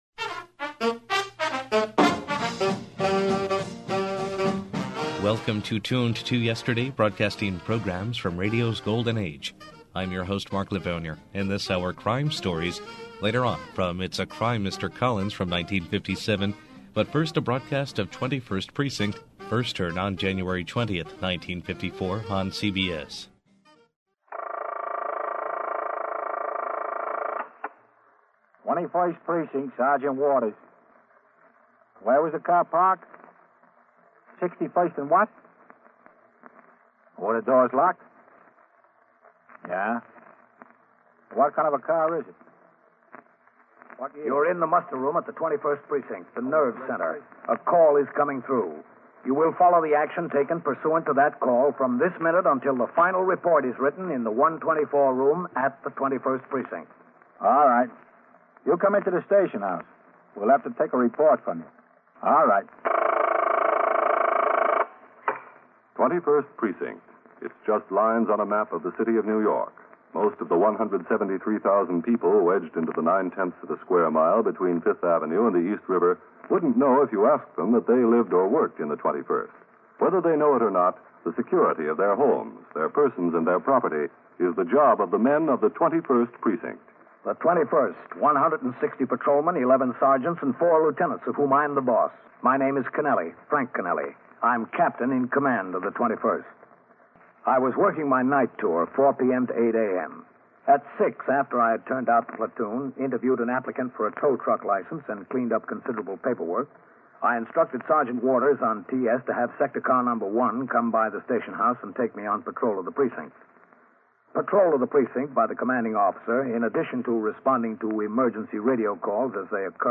The highest quality broadcasts are restored and played as they were heard years and years ago.
Audio Drama